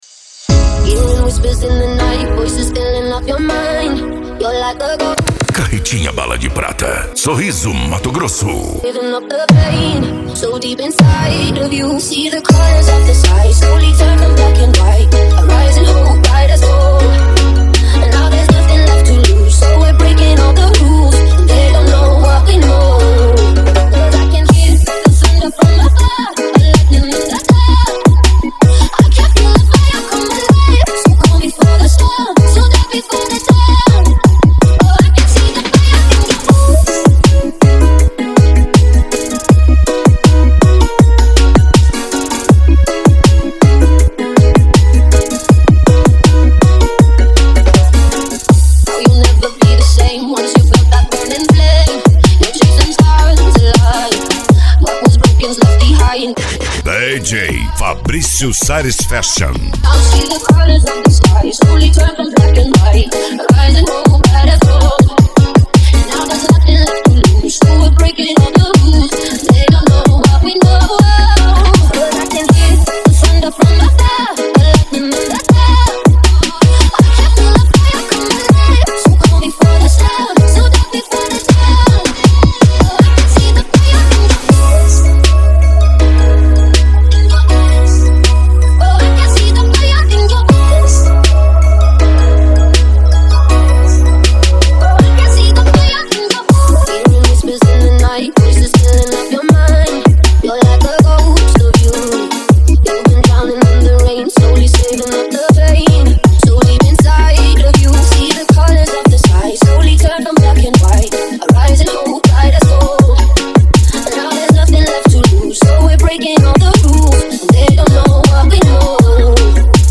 Deep House
Remix
SERTANEJO
Sertanejo Universitario